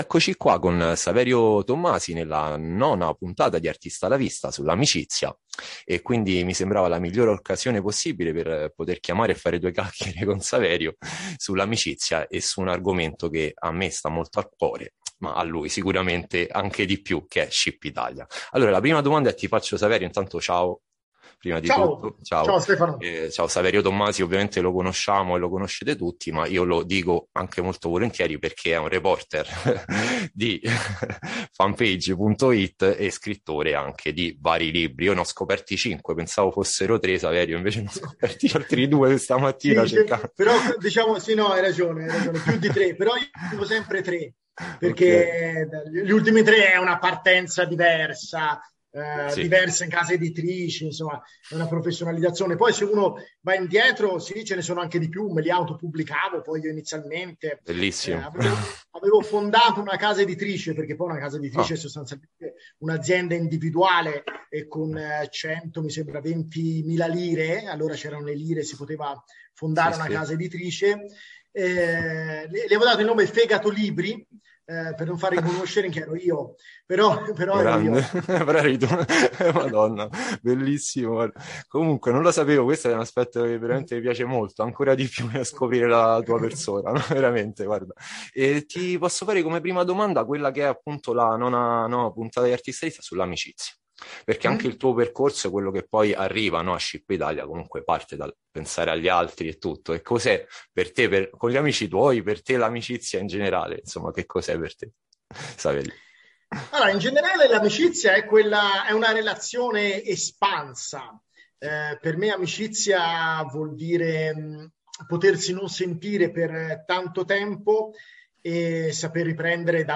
ARTISTA A LA VISTA | INTERVISTA